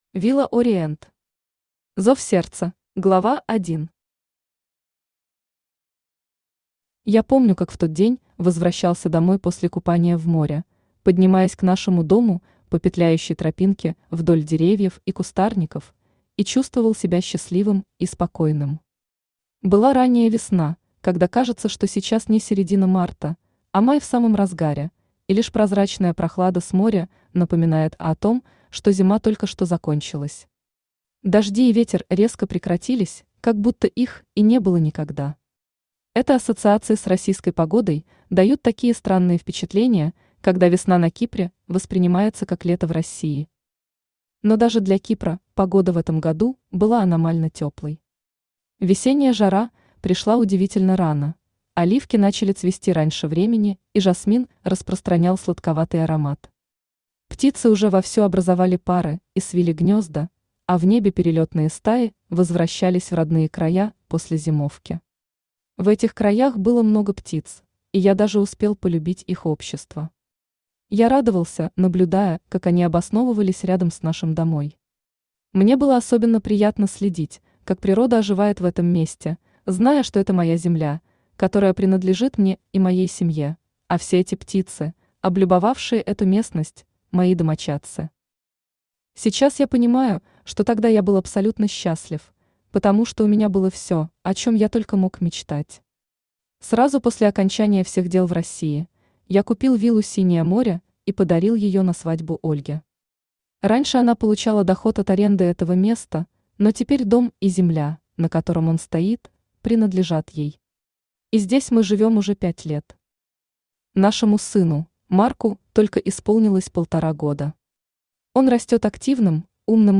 Аудиокнига Зов сердца | Библиотека аудиокниг
Aудиокнига Зов сердца Автор Villa Orient Читает аудиокнигу Авточтец ЛитРес.